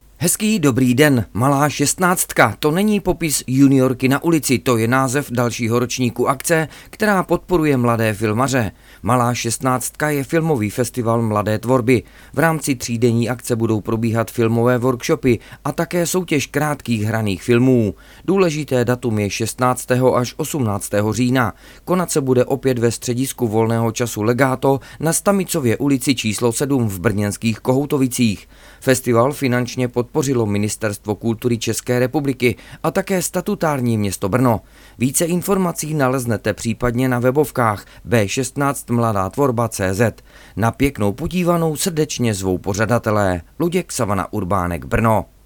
Upoutávka Rádia Signál